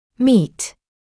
[miːt][миːт]встречать; встречаться; соответствовать (to meet requirements соответствовать требованиям)